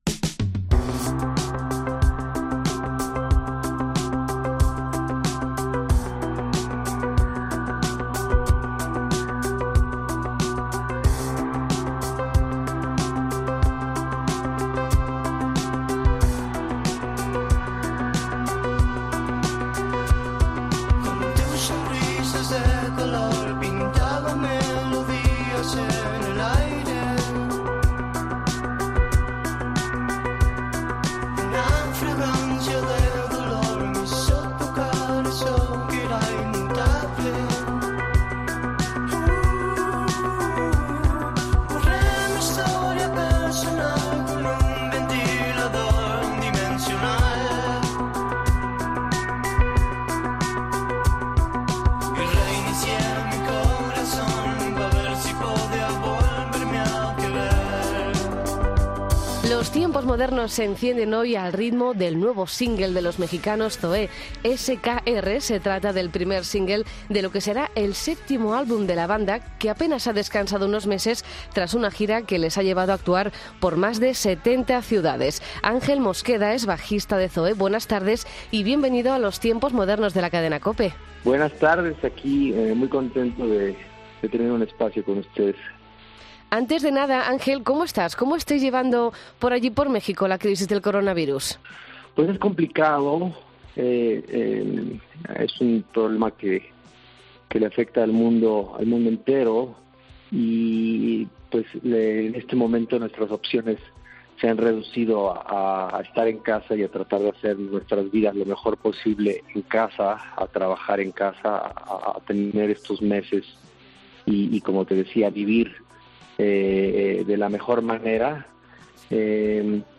Entrevista a Zoé en los Tiempos Modernos